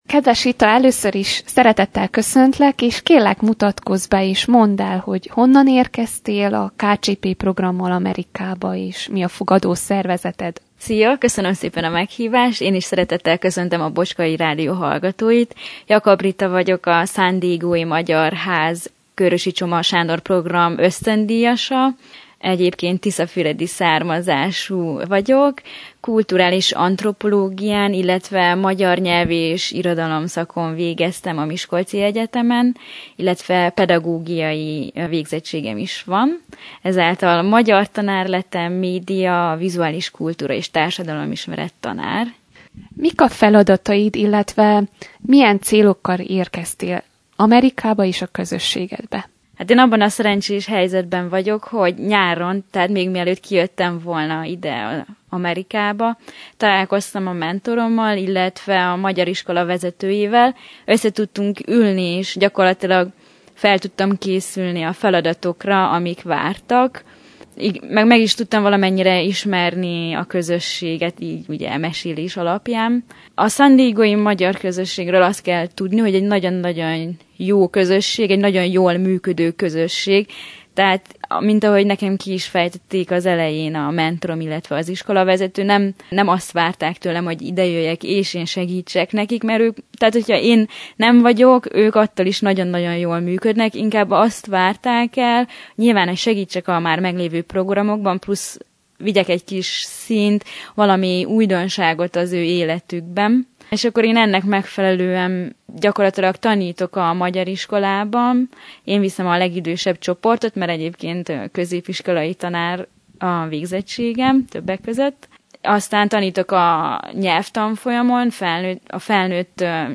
Az ösztöndíjassal készült teljes interjút az alábbi hivatkozásra kattintva hallgathatják meg. https